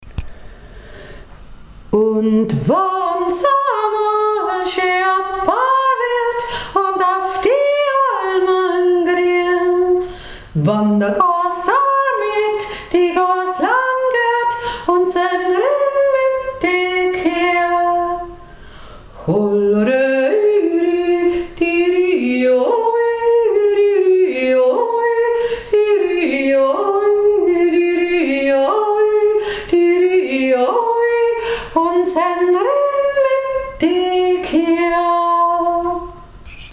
1. Stimme